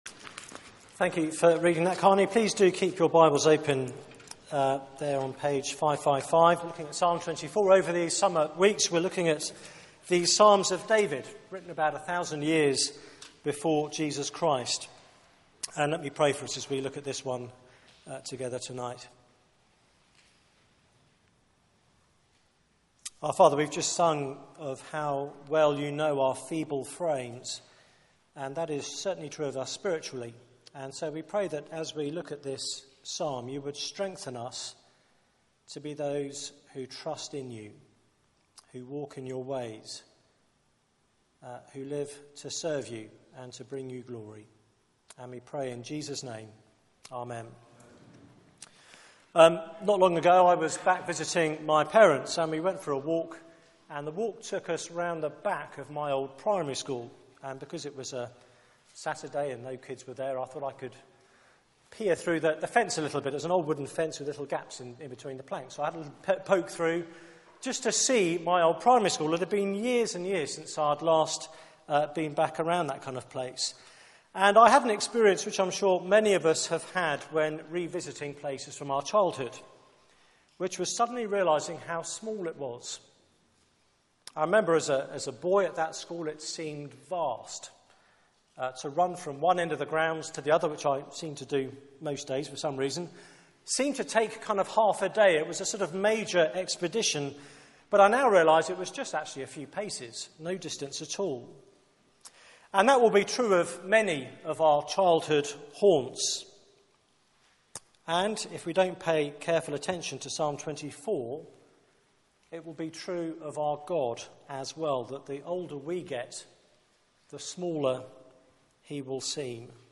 Media for 6:30pm Service on Sun 27th Jul 2014 18:30 Speaker
Psalm 24 Series: Summer Songs Theme: 'Who may stand in his holy place?' Sermon Sermon: Presentation Slides Open Search the media library There are recordings here going back several years.